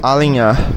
Ääntäminen
UK : IPA : /laɪn.ˈʌp/ US : IPA : /laɪn.ˈʌp/